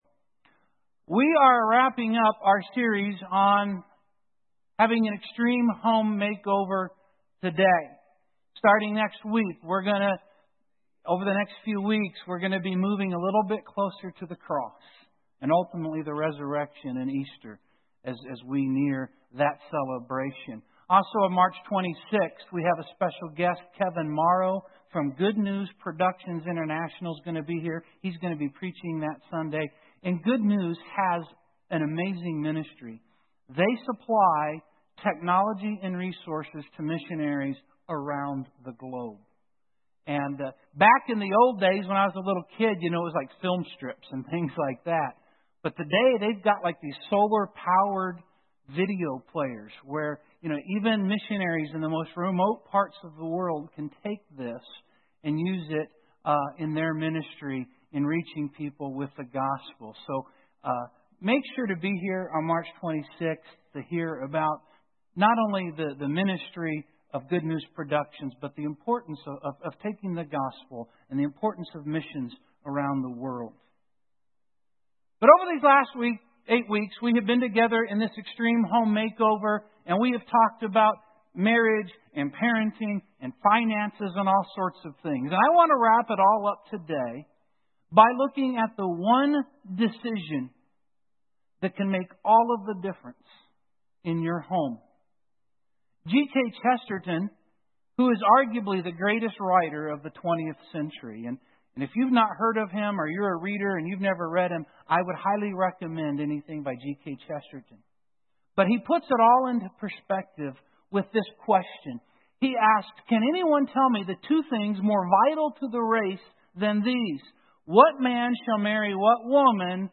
Parenting Joshua Extreme Home Makeover Audio Sermon Save Audio Save PDF This Sunday we finish our extreme home makeover. We will step back in time 3,500 years to look at the life of Joshua, the second leader of the nation of Israel, and their commander and chief who led them in their conquest of the Promised Land.